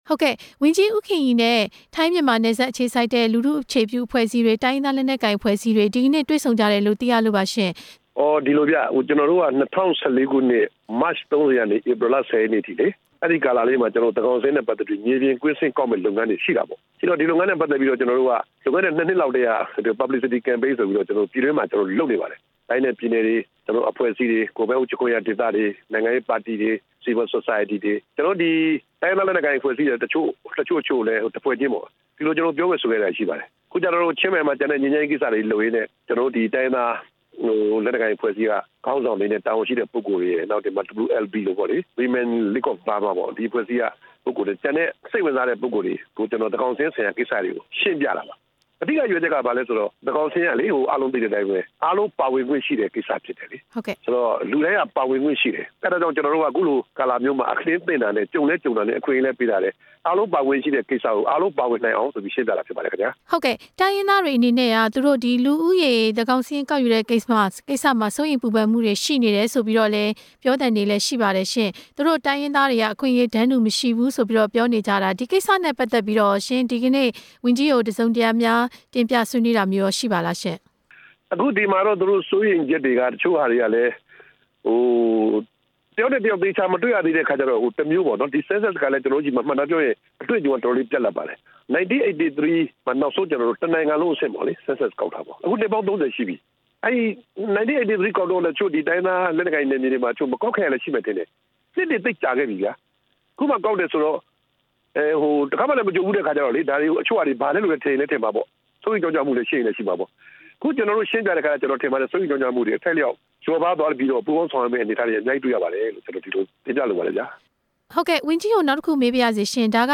ဝန်ကြီး ဦးခင်ရီနဲ့ ဆက်သွယ်မေးမြန်းချက်